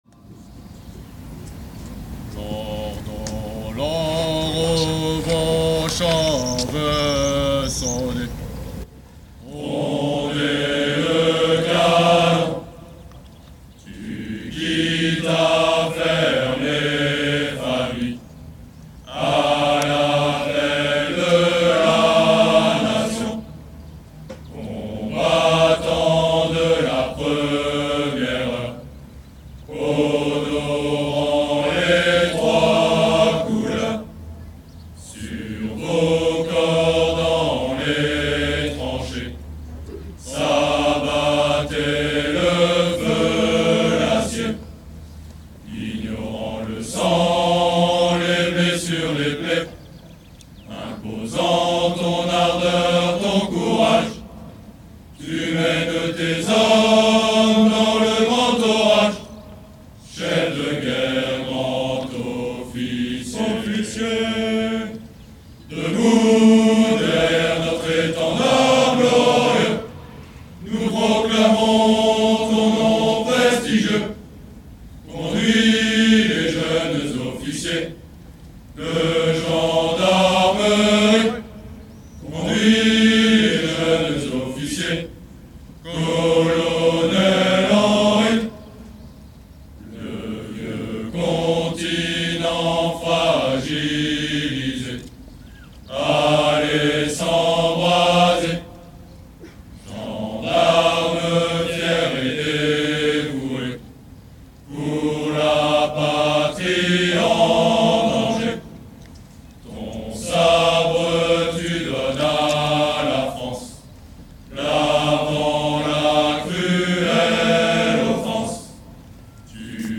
Chant de promotion EOGN 2012 Colonel Adrien Henry (MP3)